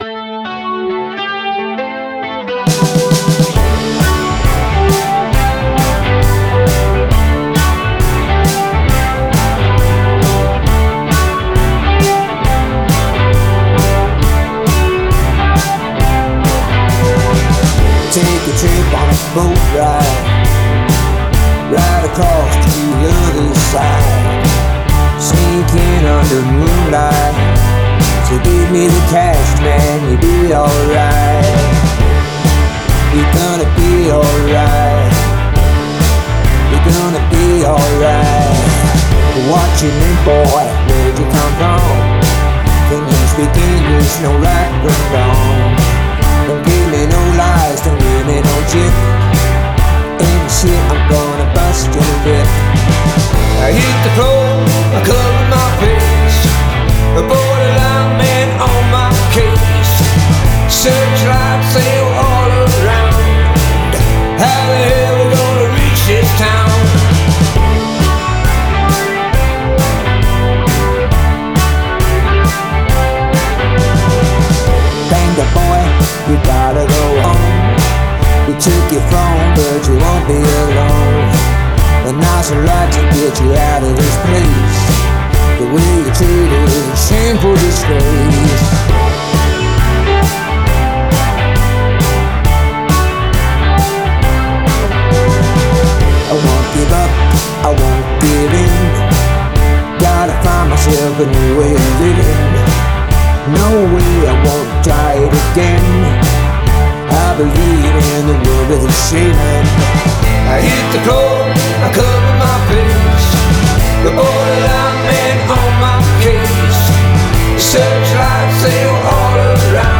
Lt Renderfile sind es exakt -7,5 LUFS Dein Browser kann diesen Sound nicht abspielen.
Hammerhart du hast den Mix echt extrem verdichtet, so was hab ich selten erlebt.